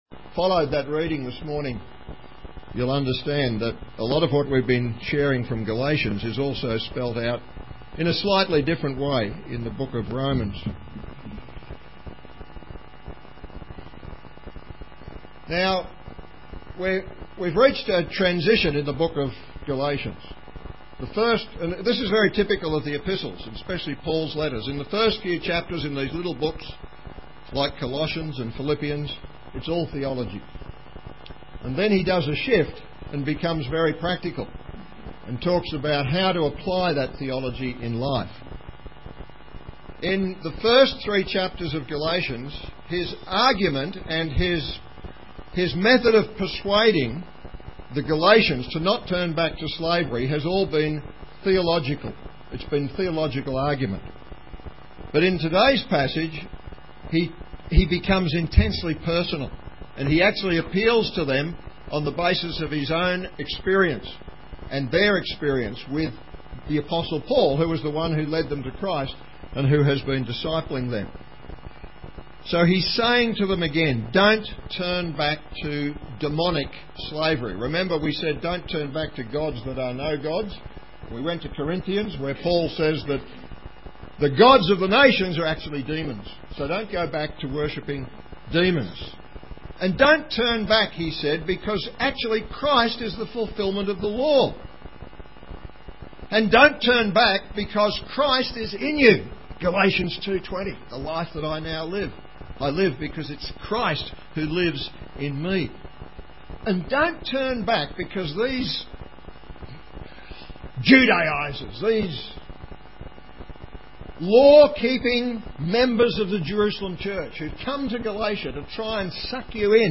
Galatians 4:12-20 Listen to the sermon here.